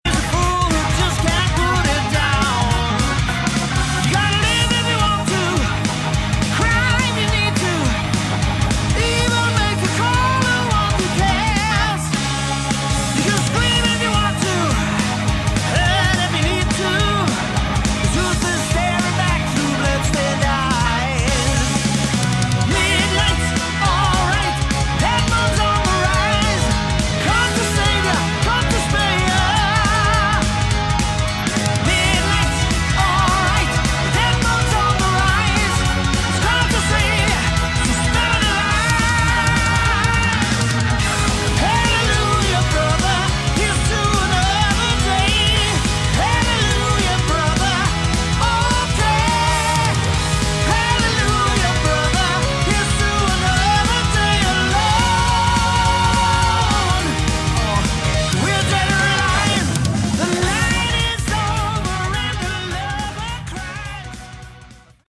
Category: Hard Rock
Vocals
Guitars